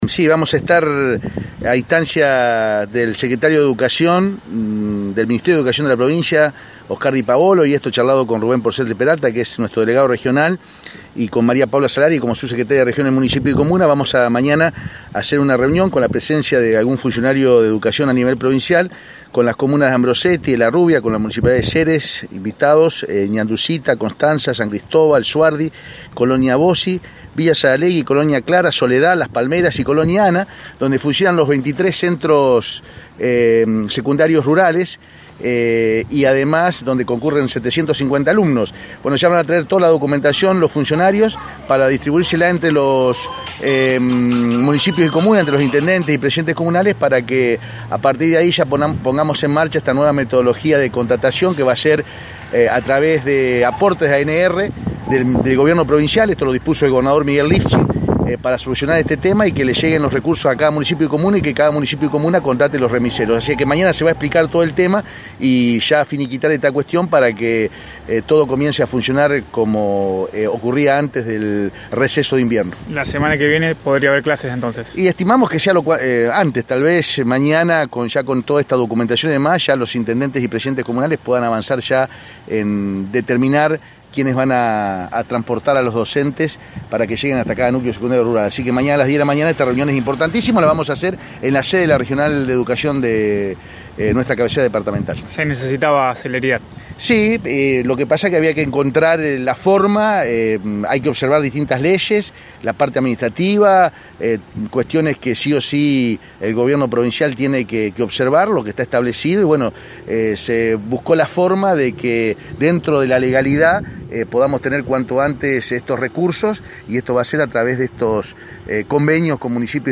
Lo aseguró a Radio EME, el Senador por el Departamento San Cristóbal, Felipe Michilg, quien sostuvo que «antes del viernes podrían volver las clases».